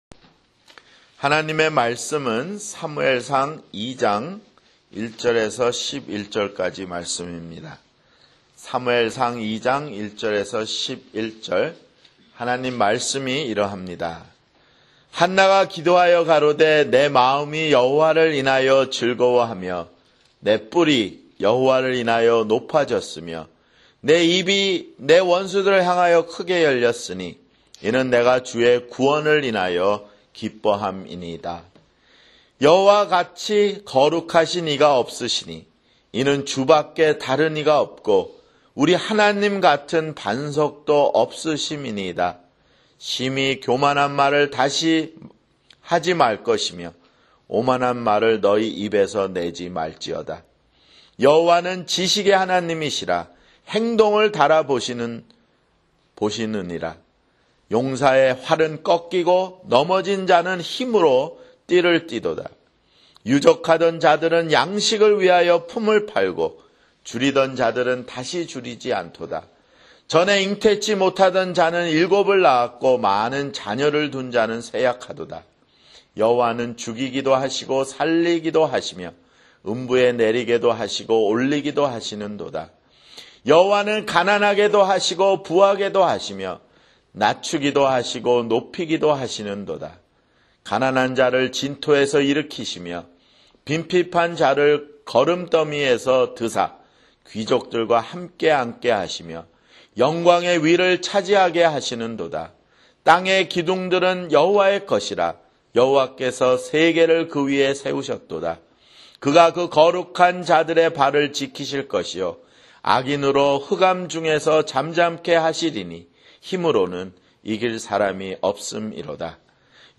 [주일설교] 사무엘상 (11)